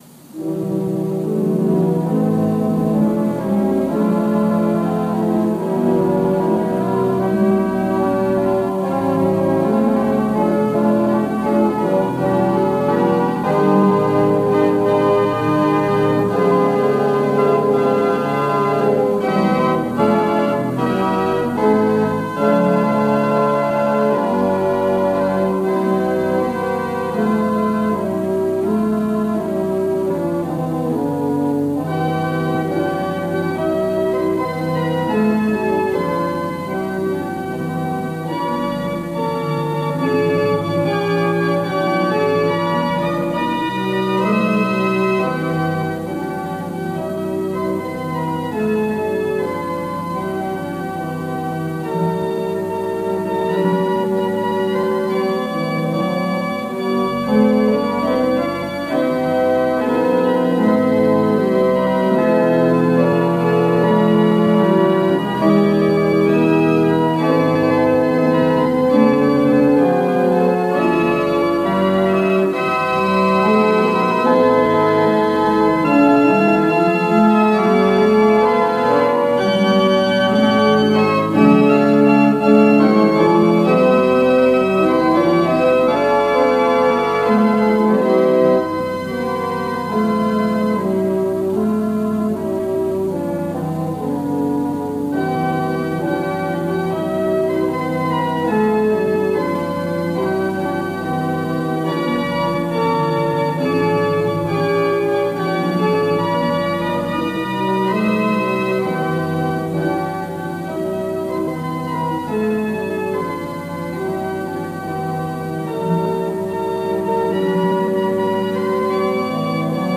Palm Sunday Worship Service March 28, 2021 | First Baptist Church, Malden, Massachusetts
Call to Worship, Invocation & Lord’s Prayer